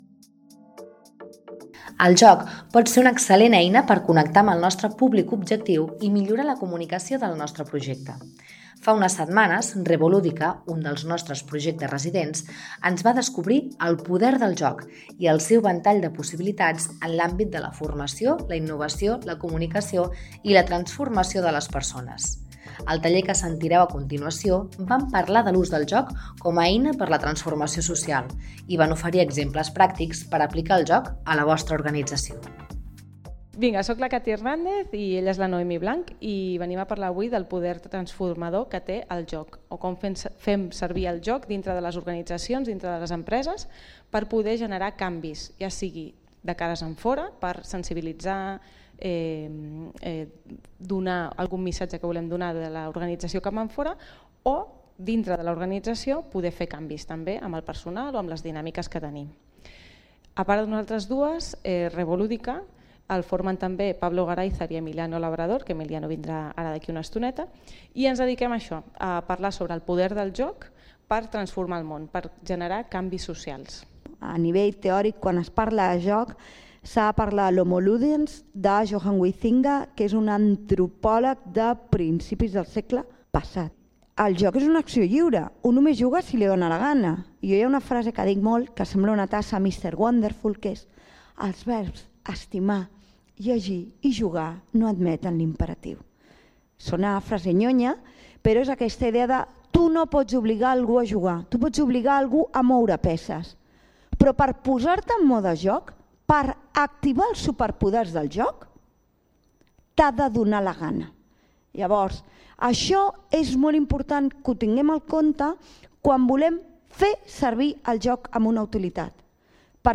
Al taller que sentireu a continuació van parlar de l’ús del joc com a eina per a la transformació social i van oferir exemples pràctics per aplicar el joc a la vostra organització.